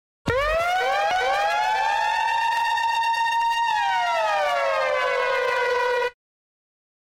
描述：经典的空袭警报器，上面有一点延迟。
Tag: 炸弹 舞厅 警报器 警报 空气 RAID